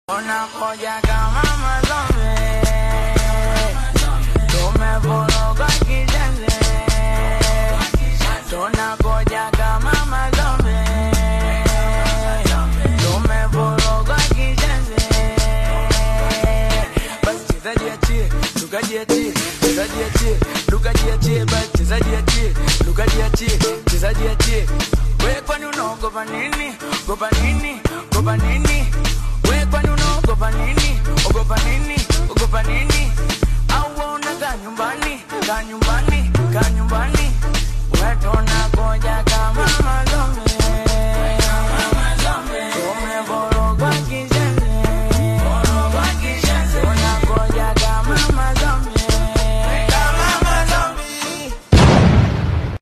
high-energy Amapiano/Singeli fusion snippet
Genre: Amapiano